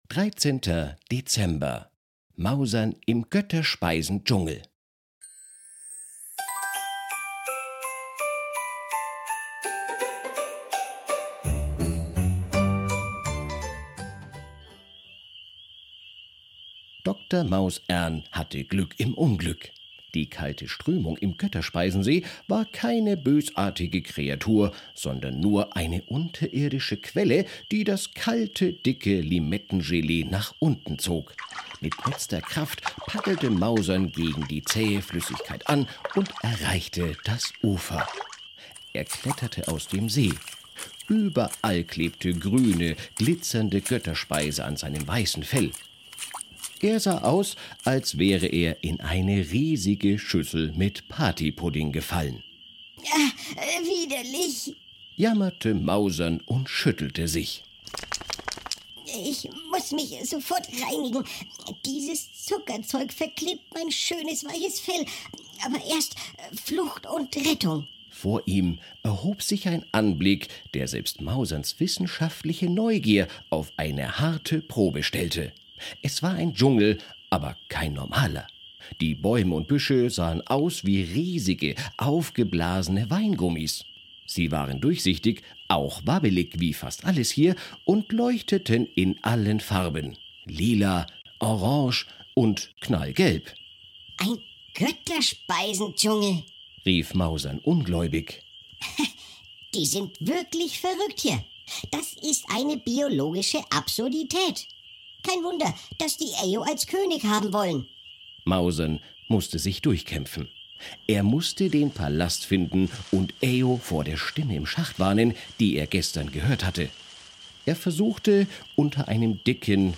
Ein Kinder Hörspiel Adventskalender
VoiceOver: